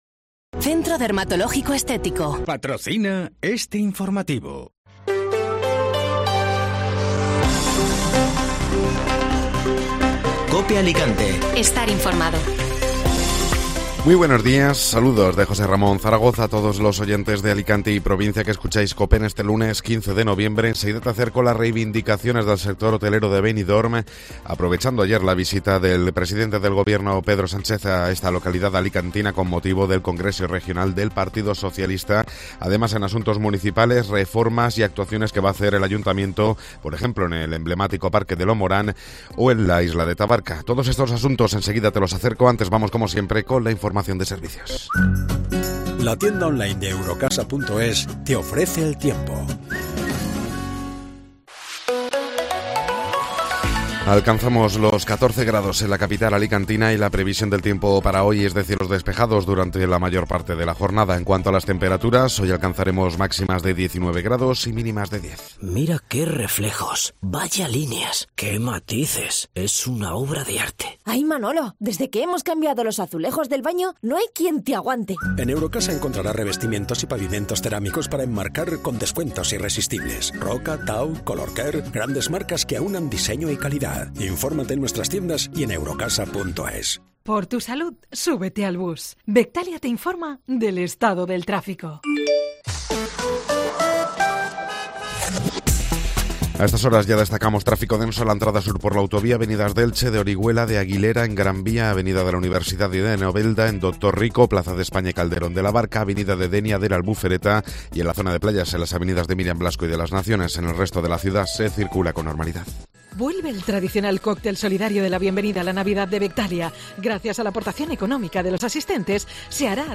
Alicante - Novelda